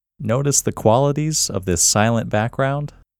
QUIETNESS Male English 16
The-Quietness-Technique-Male-English-16.mp3